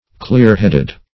Search Result for " clear-headed" : The Collaborative International Dictionary of English v.0.48: Clear-headed \Clear"-head`ed\ (kl[=e]r"h[e^]d`[e^]d), a. Having a clear understanding; quick of perception; intelligent.
clear-headed.mp3